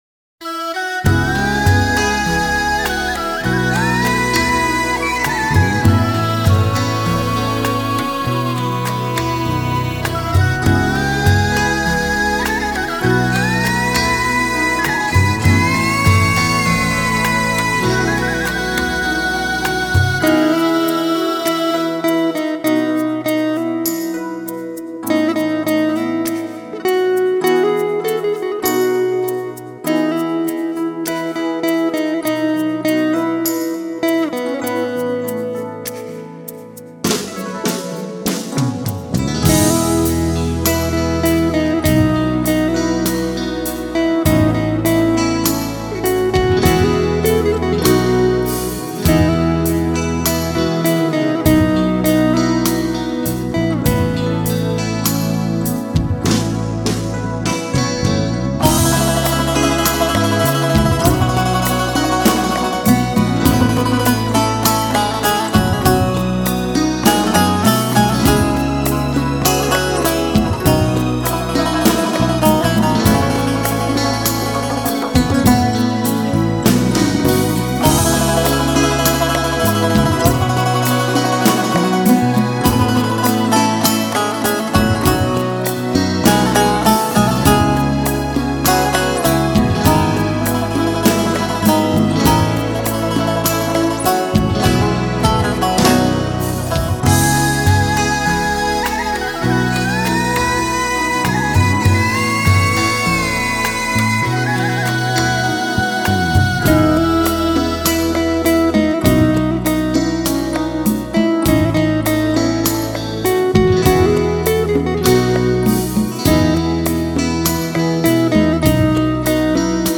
[نوع آهنگ: لایت]